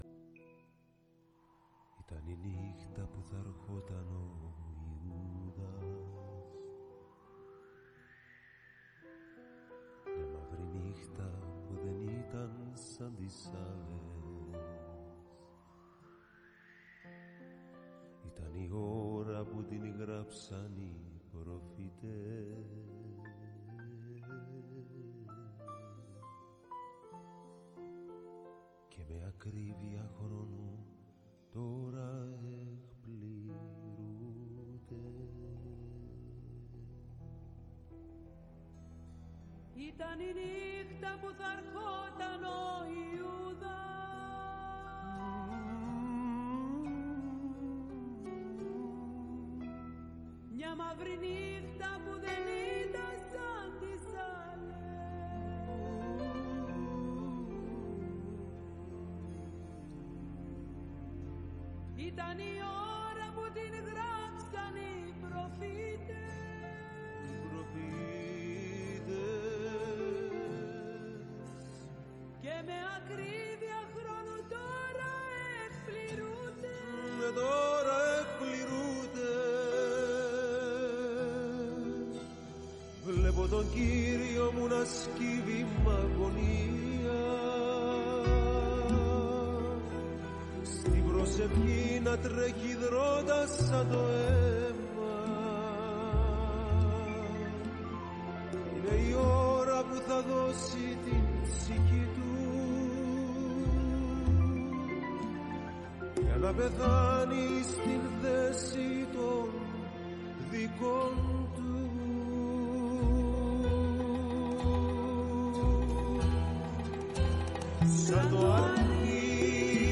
ΤΟ ΤΡΑΓΟΥΔΙ ΣΤΗΝ ΑΡΧΗ ΚΑΙ ΣΤΟ ΤΕΛΟΥΣ ΤΟΥ ΜΗΝΥΜΑΤΟΣ